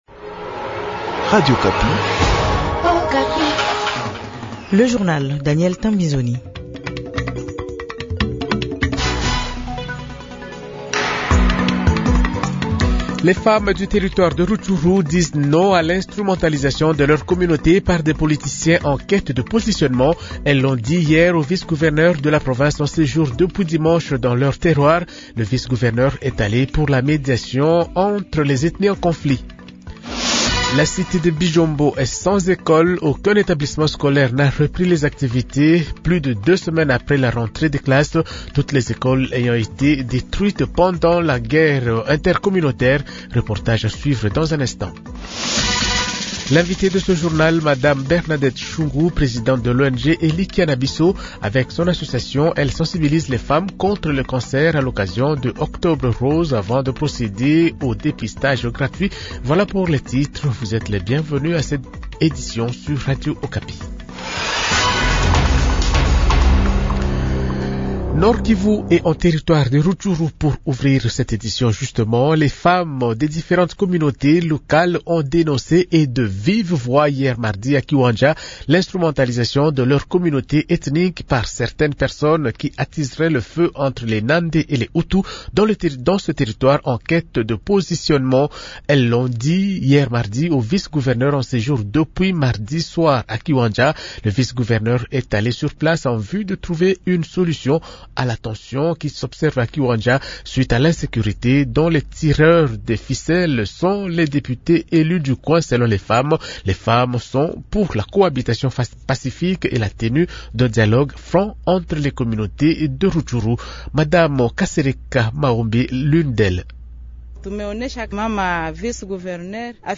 Journal Francais Matin 8h00